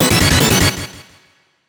ALERT_Dissappear.wav